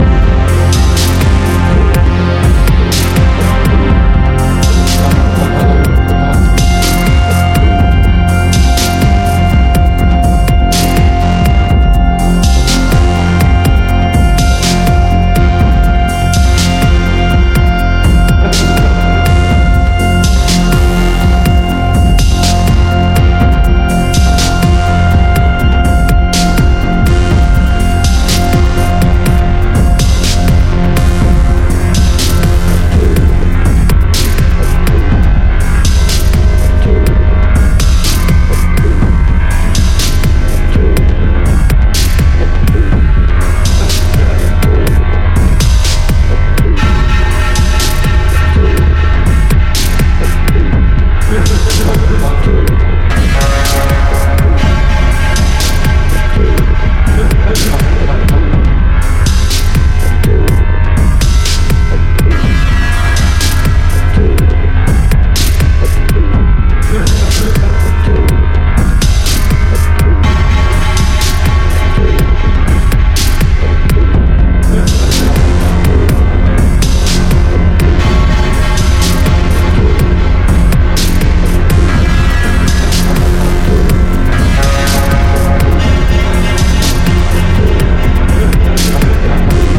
atmospheric low tempo beats
heavy bass club orientated records
Electro Electronix Techno